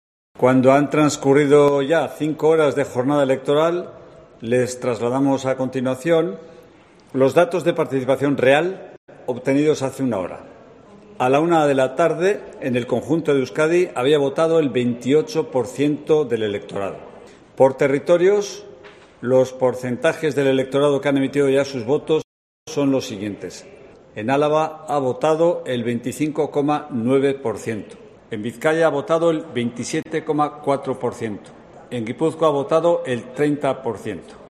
Josu Erkoreka, vicelehendakari, anuncia un 28% de participación hasta las 13:00 horas